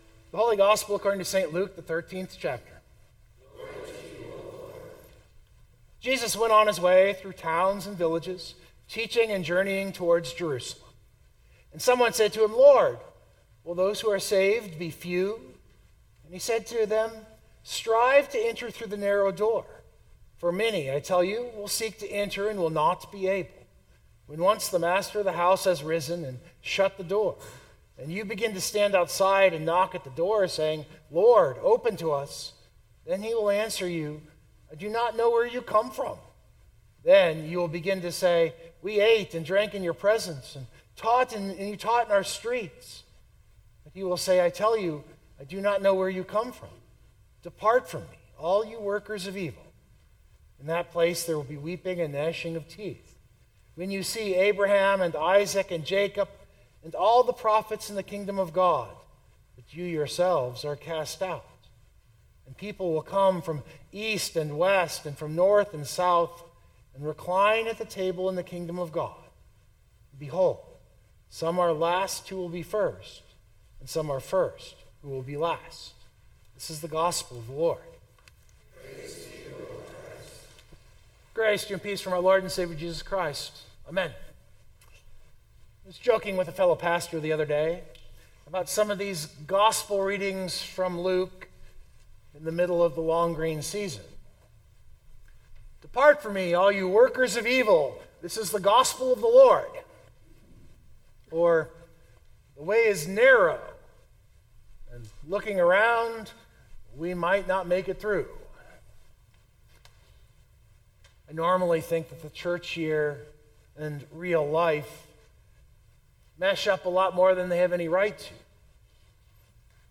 This sermon is structured around a 2×2 box of motivation behind the question.